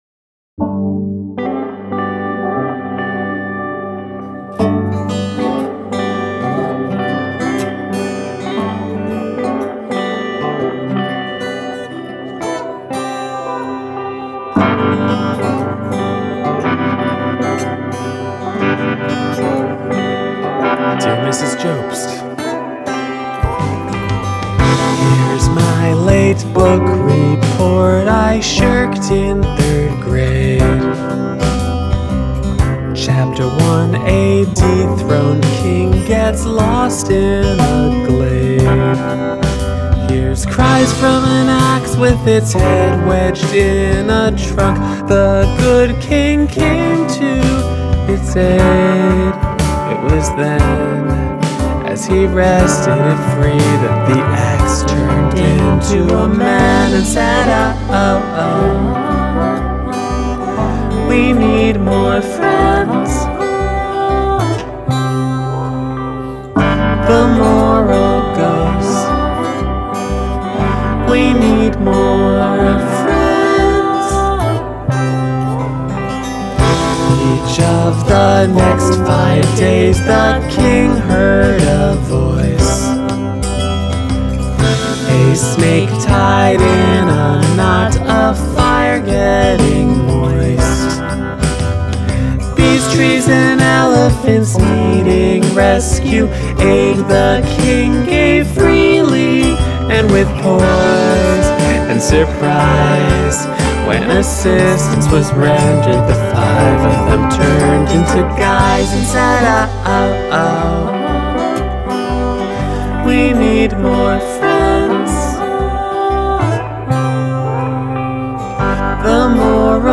additional vocals